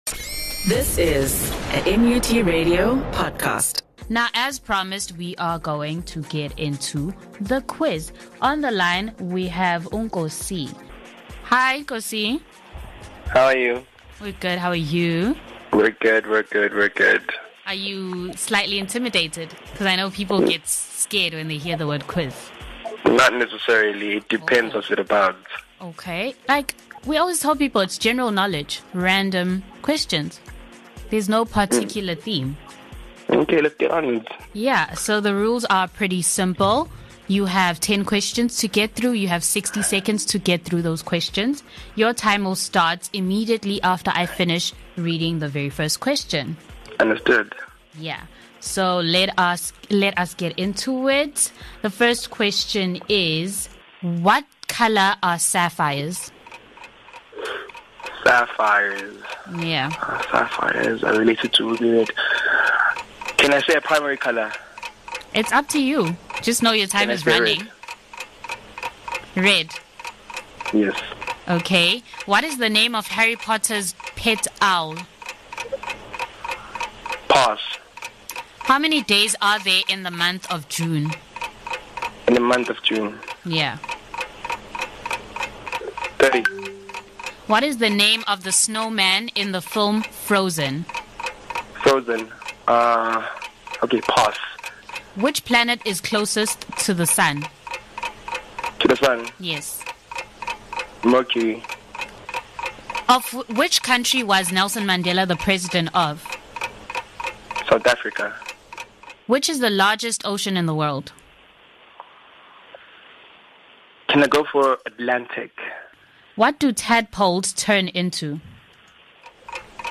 A one minute quiz, (sixty second - ten questions) is a knowledge-testing activity where a guest answers ten questions in a minute.